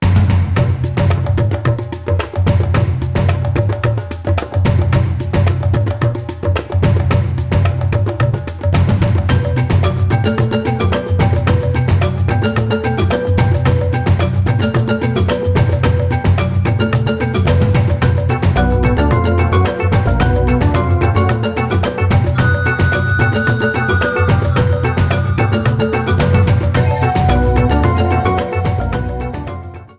Ripped from the ISO
Faded in the end